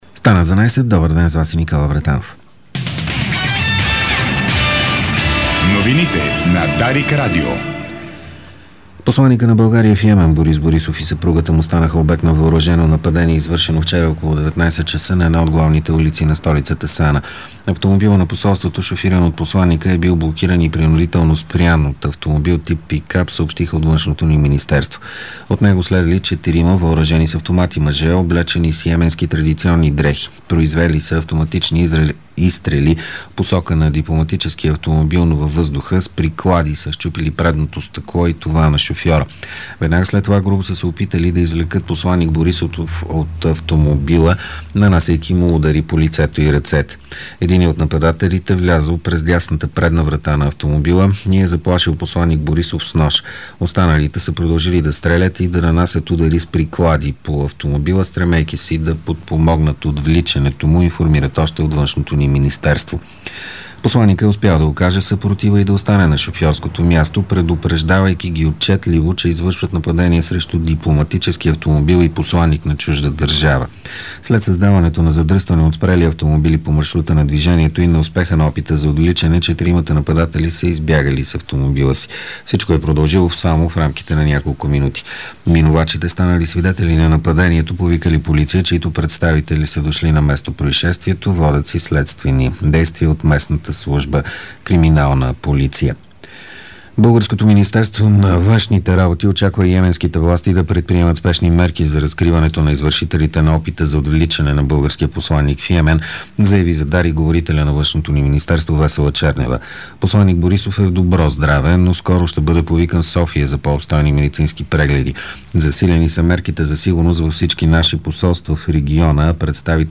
Обедна информационна емисия - 13.05.2012